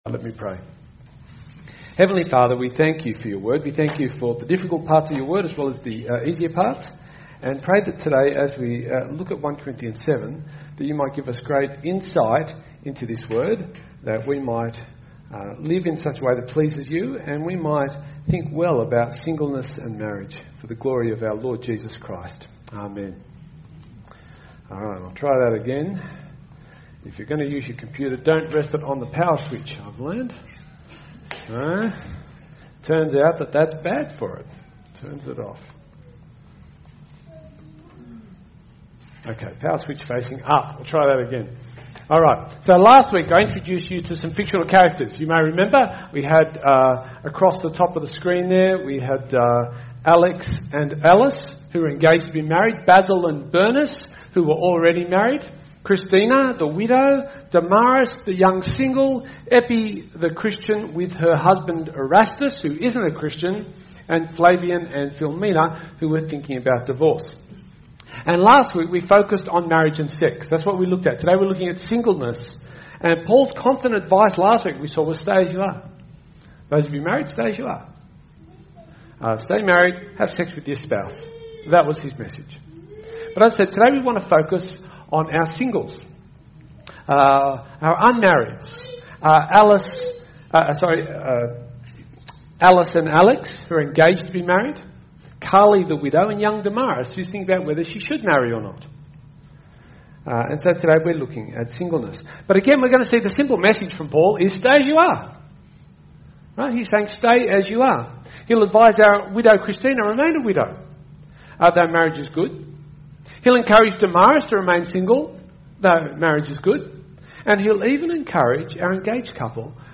SERMON – Singleness